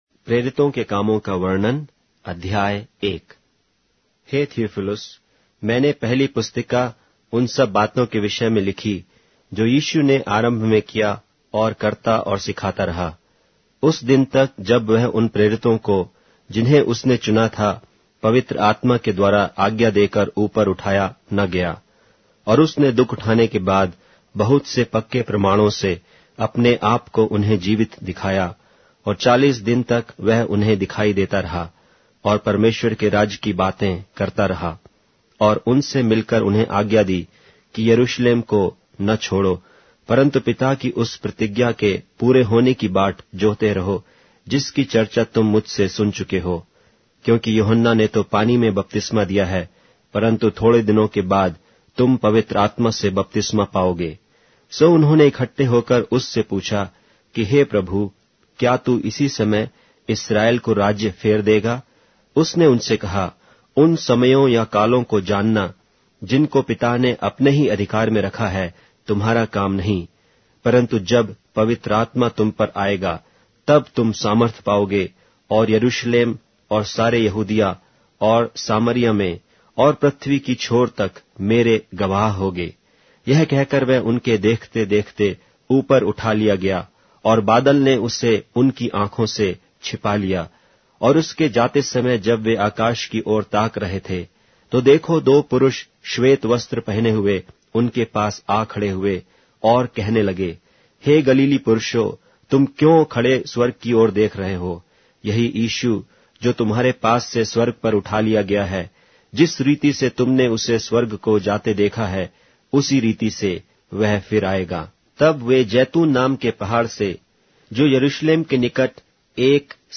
Hindi Audio Bible - Acts 12 in Bnv bible version